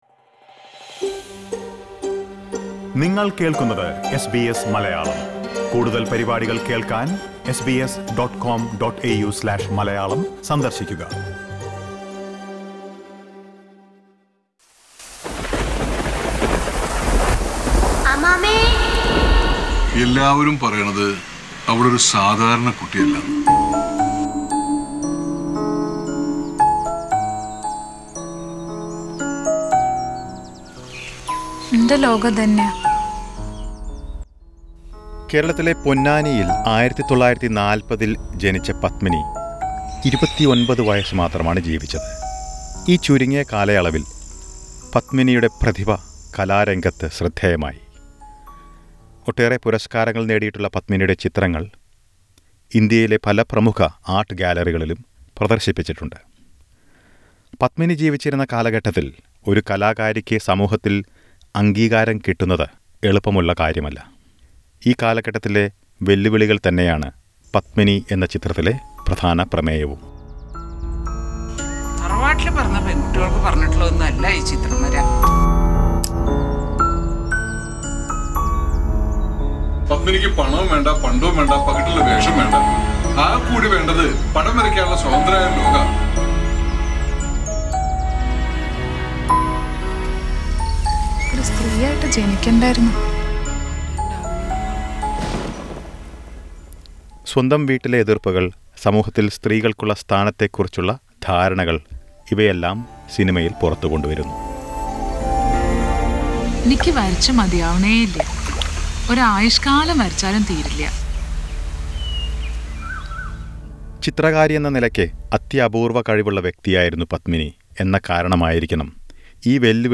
A film about artist TK Padmini was screened in Melbourne recently. Lsiten to a report.